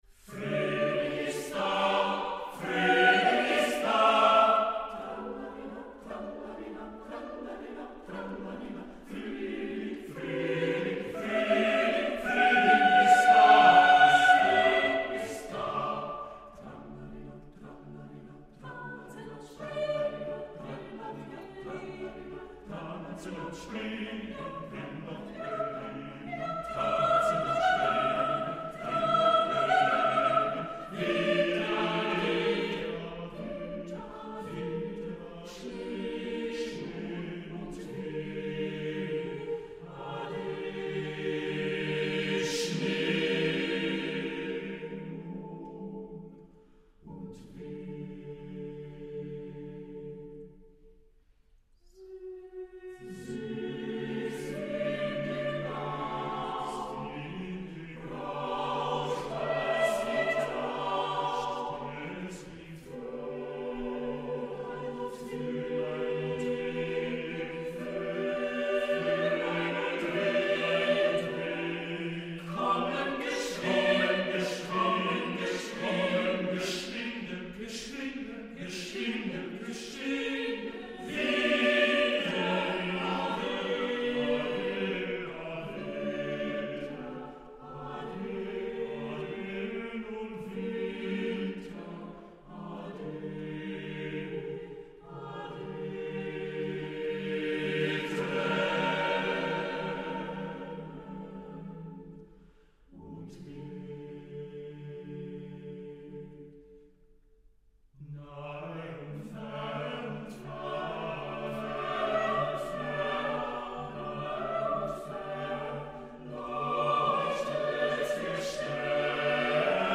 Incontro con il direttore d’orchestra